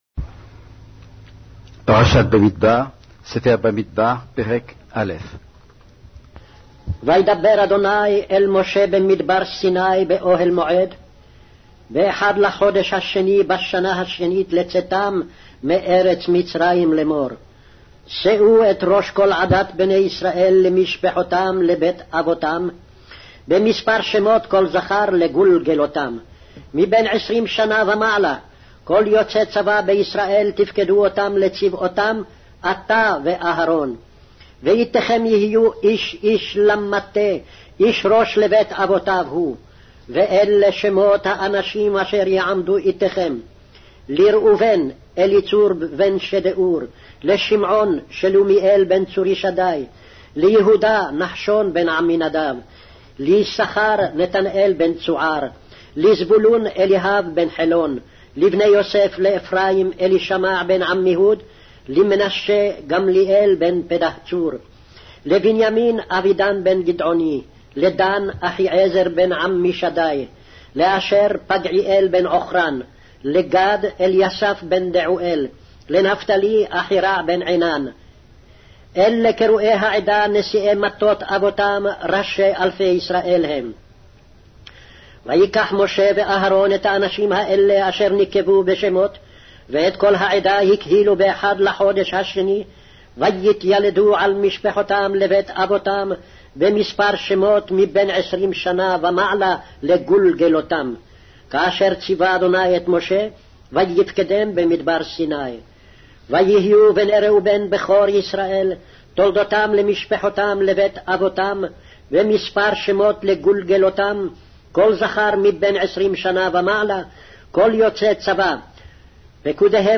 Hebrew Audio Bible - Numbers 3 in Ncv bible version